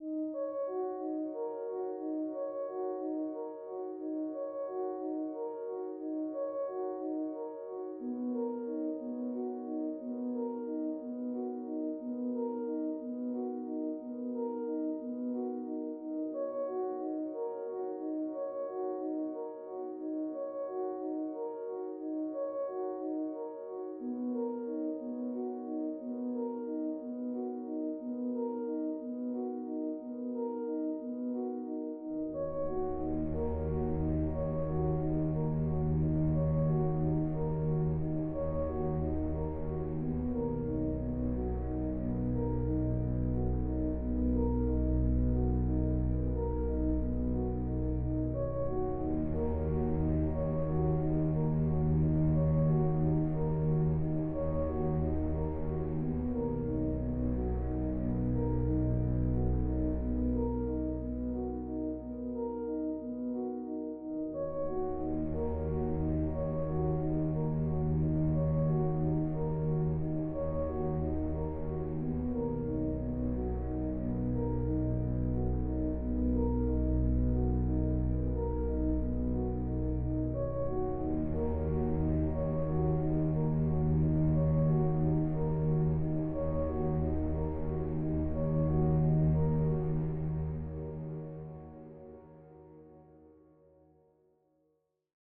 Added Ambient music pack. 2024-04-14 17:36:33 -04:00 26 MiB Raw Permalink History
Ambient Nothingness Intensity 1.wav